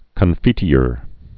(kən-fētē-ər, -ôr)